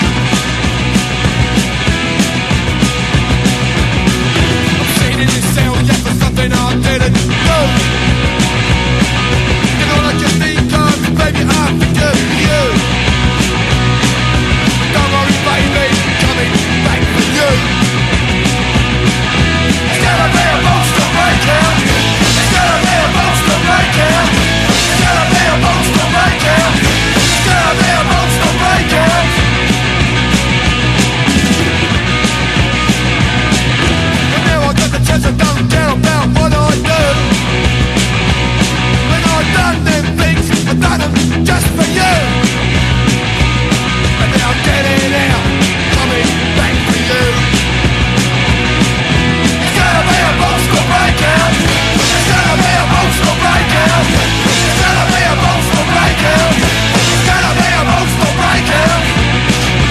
ROCK / 70'S